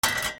なべ コンロにかける『カラン』